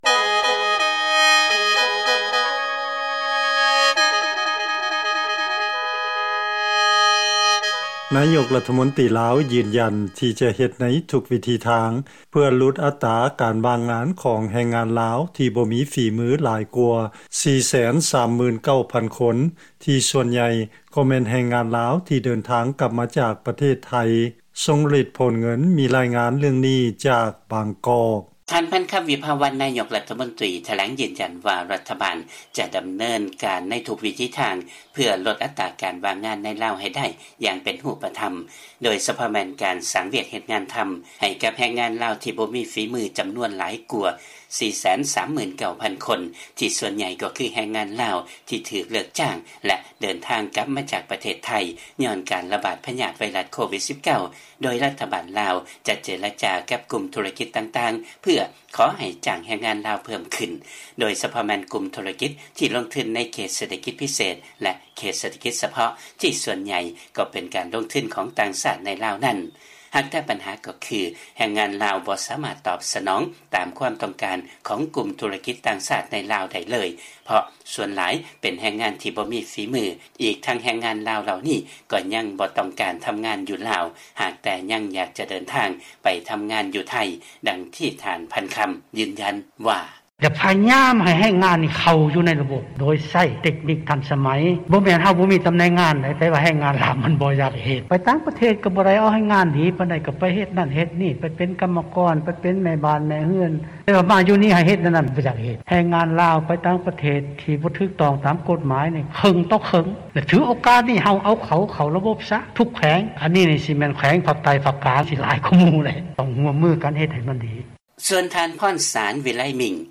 ເຊີນຟັງລາຍງານກ່ຽວກັບ ນາຍົກລັດຖະມົນຕີລາວຢືນຢັນທີ່ຈະເຮັດໃນທຸກວິທີທາງ ເພື່ອຫຼຸດອັດຕາການຫວ່າງງານຂອງແຮງງານລາວ